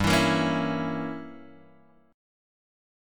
G Diminished 7th